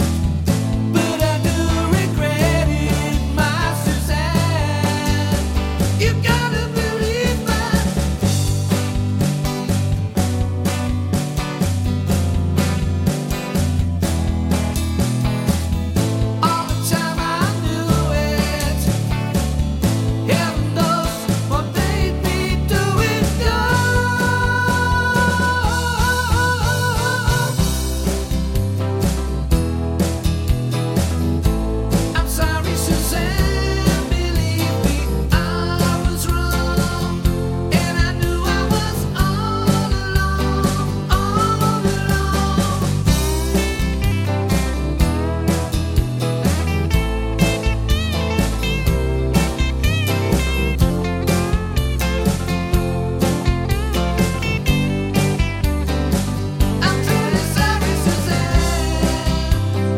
Pop (1960s)